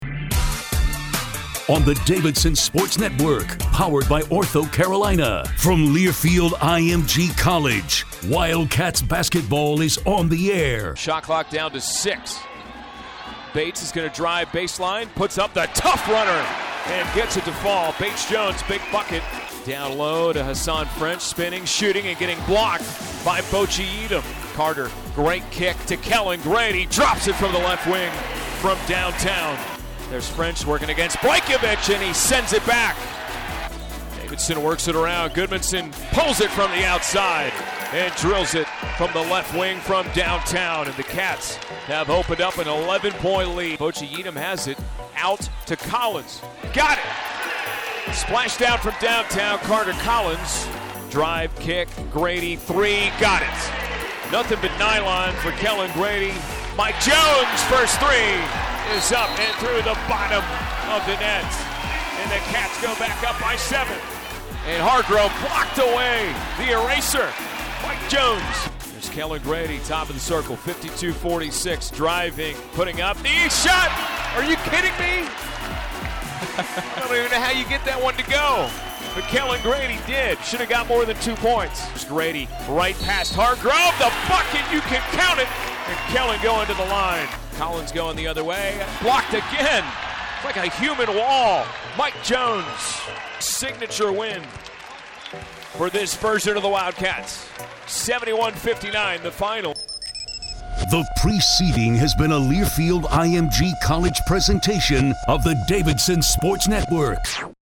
Radio Highlights